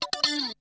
7TH HIT   -L.wav